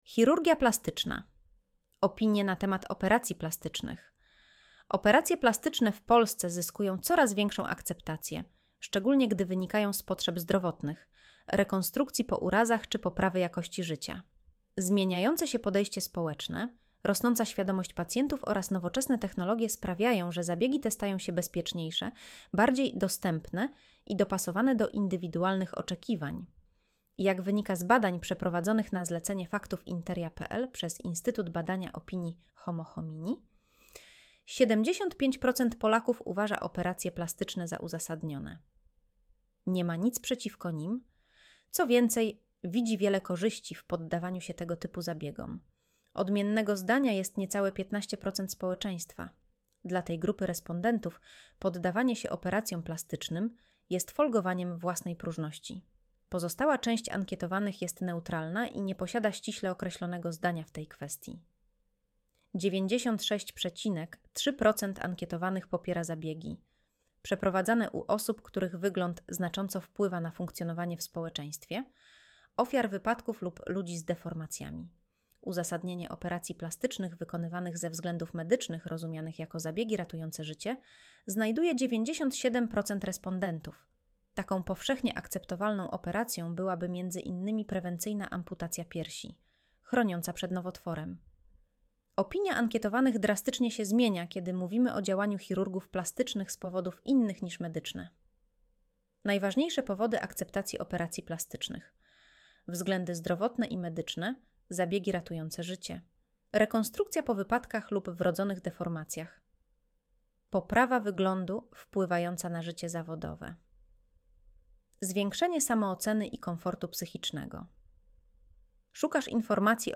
Streść artykuł Słuchaj artykułu Audio wygenerowane przez AI, może zawierać błędy 00:00